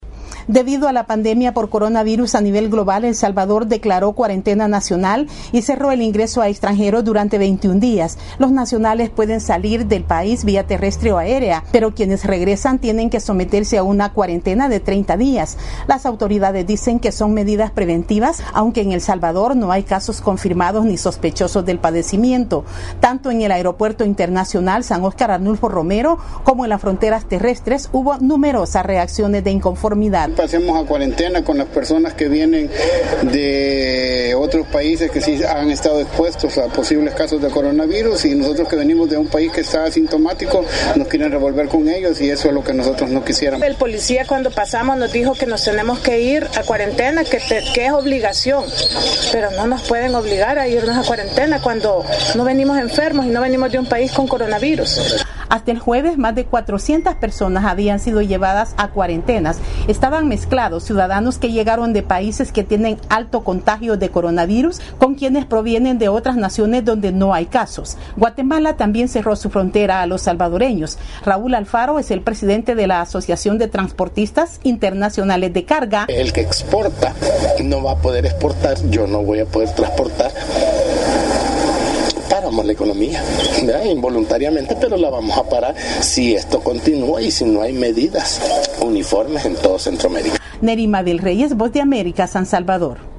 VOA: Informe de El Salvador